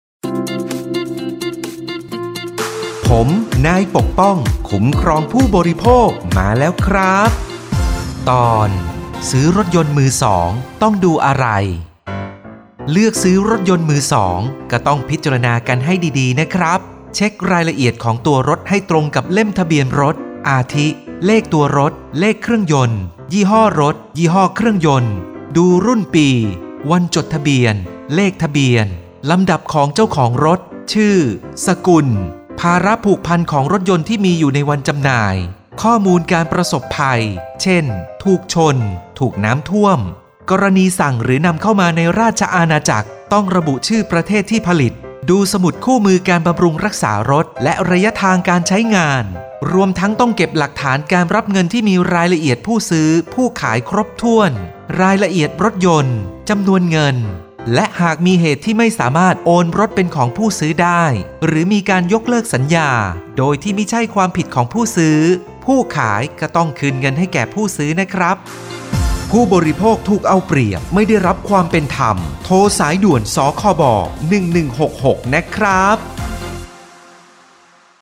สื่อประชาสัมพันธ์ MP3สปอตวิทยุ ภาคกลาง
029.สปอตวิทยุ สคบ._ภาคกลาง_เรื่องที่ 29_.mp3